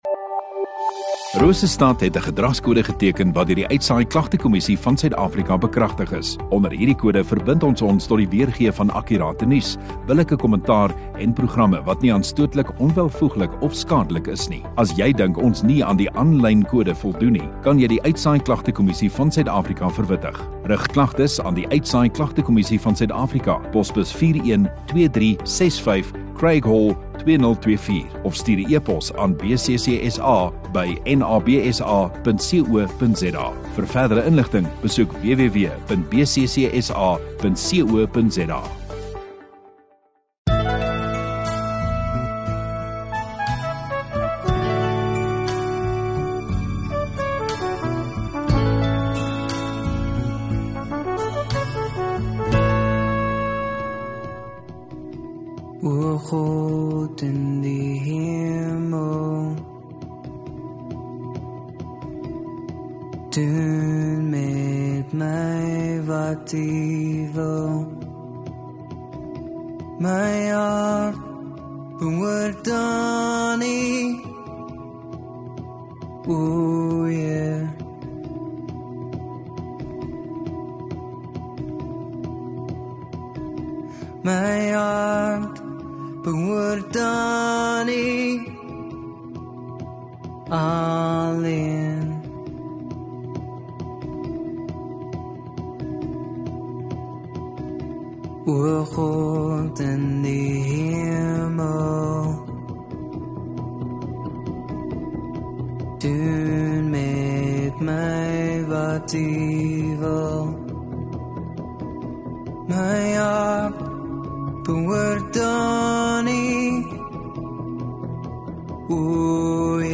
30 Mar Sondagoggend Erediens